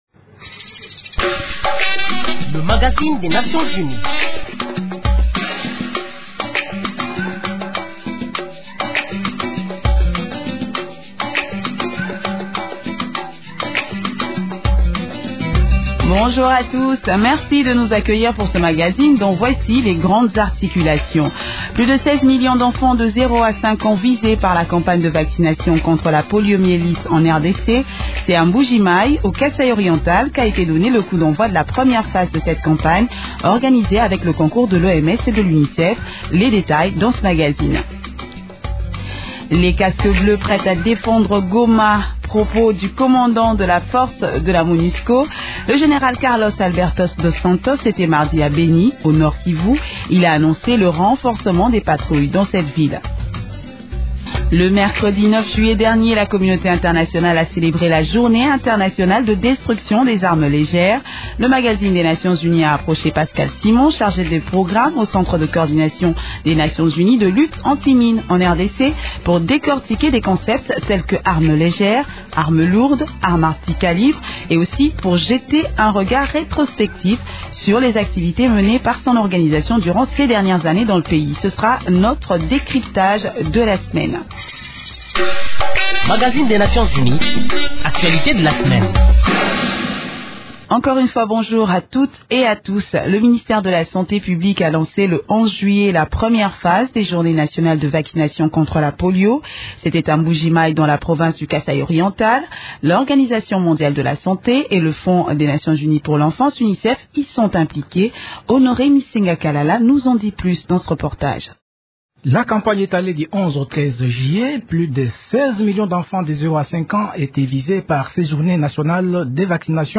Le reportage qui fait l’actualité de la semaine